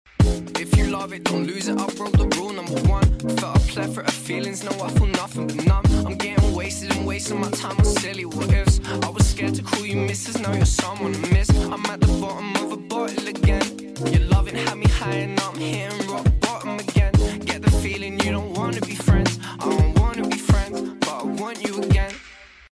UK rap